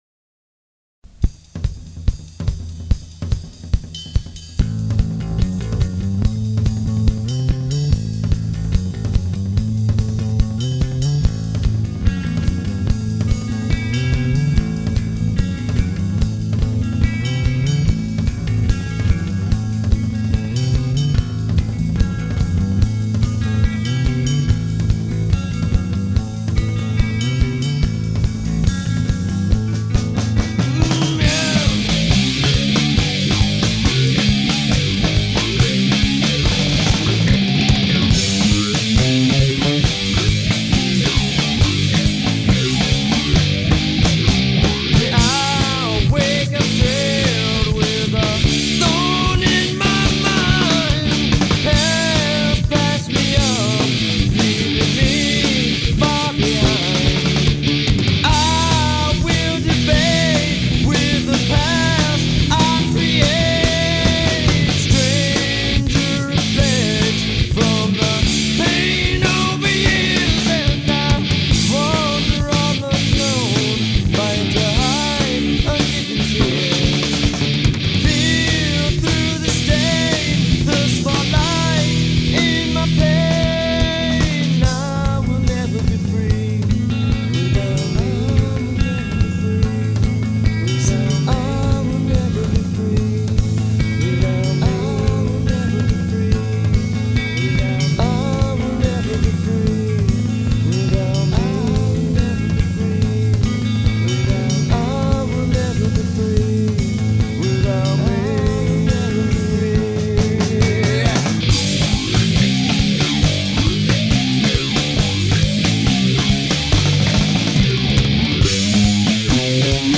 From 1994-1997 I was in an Orange County, CA based rock band, UNLEDED.
vocals
Guitar
Drums/Guitar/Vocals